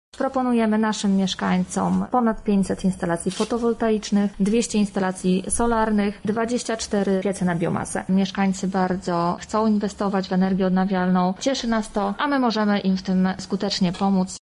– mówi Jarosław Stawiarski, marszałek województwa.